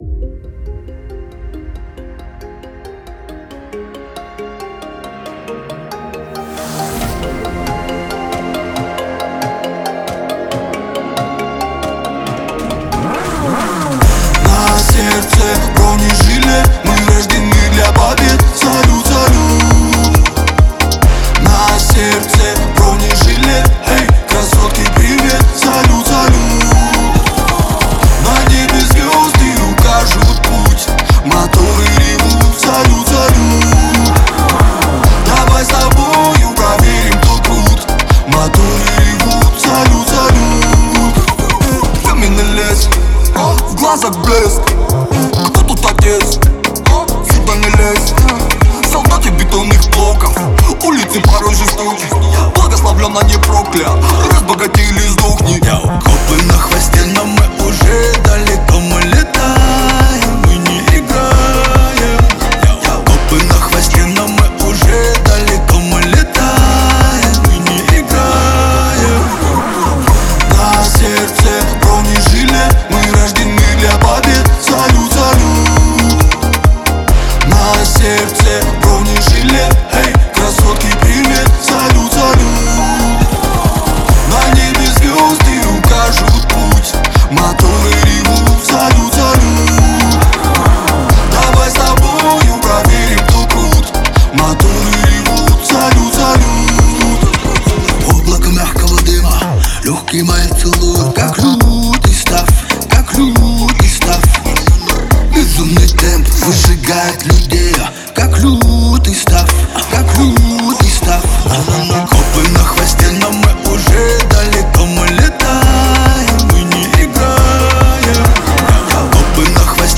яркая и энергичная песня